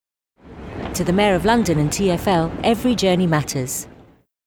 RP + British Indian (Hindi, Punjabi). Friendly, warm, reassuring, youthful, approachable, natural | Rhubarb Voices